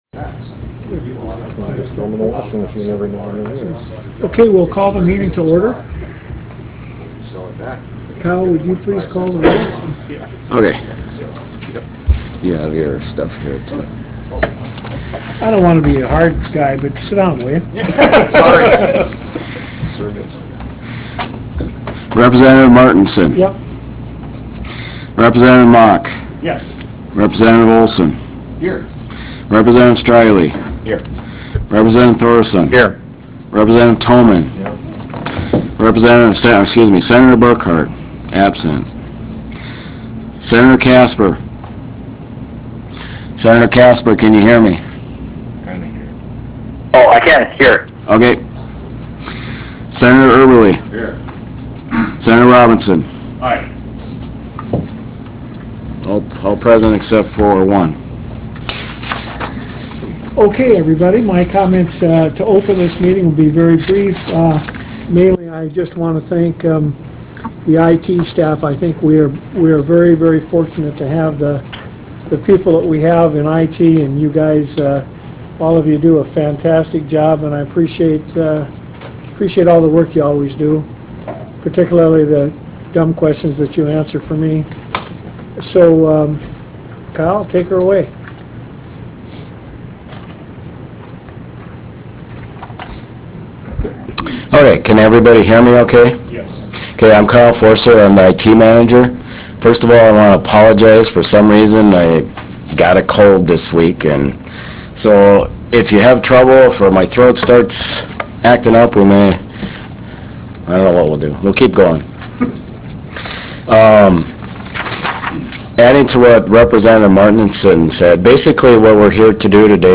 Harvest Room State Capitol Bismarck, ND United States